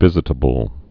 (vĭzĭ-tə-bəl)